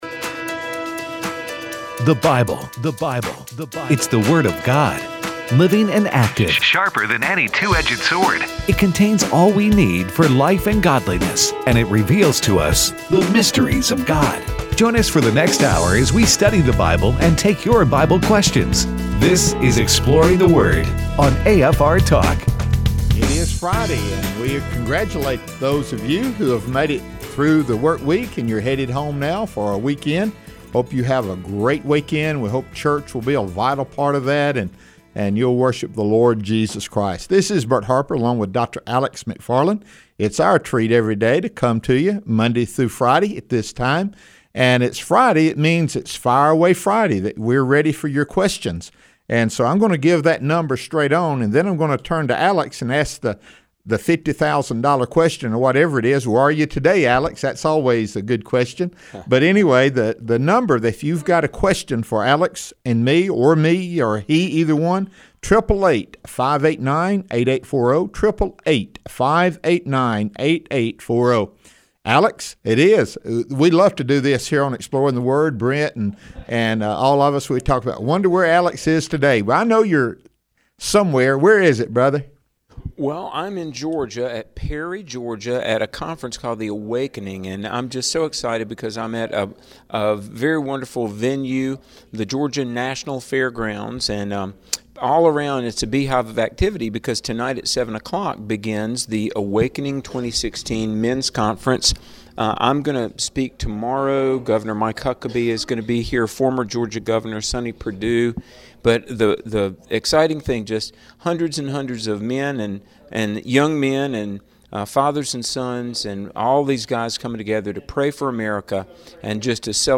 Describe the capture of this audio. take your phone calls the whole show